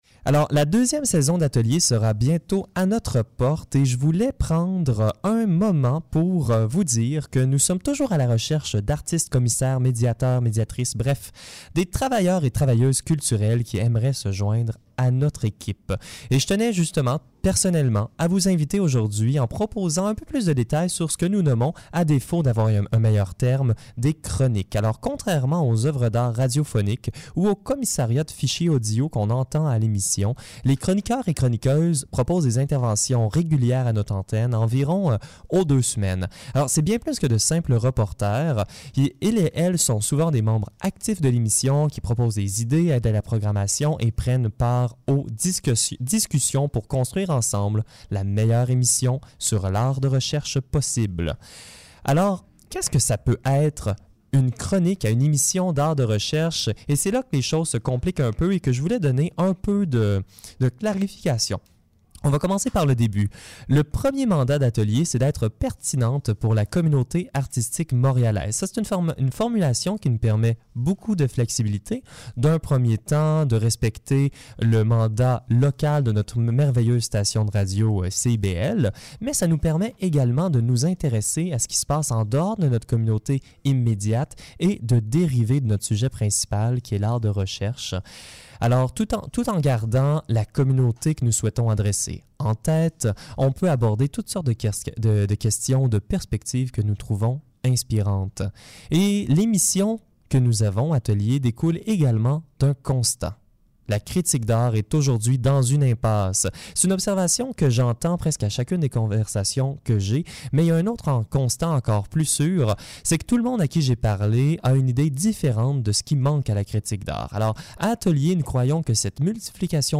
atelier est était un magazine radiophonique sur l’art actuel à CIBL 101,5 Tiohtià:ke | Montréal, les lundis, 18 H.
Chroniques